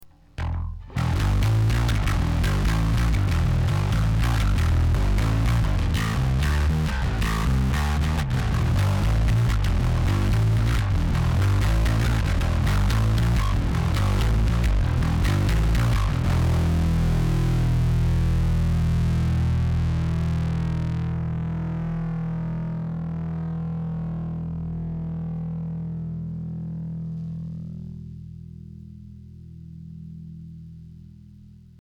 歪み系エフェクターとしてはかなりのジャジャ馬『BOSS ODB-3』
ベースは『Greco PB580』
大体、バランスをちょっとでもオーバードライブ側に振りすぎるとこんな音になります。
ギターの音になっちまいます。